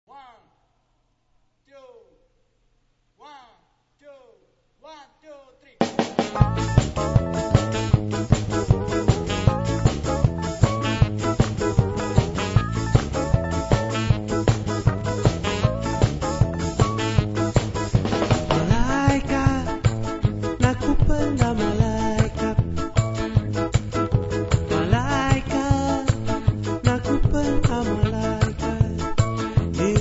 • Reggae
• registrazione sonora di musica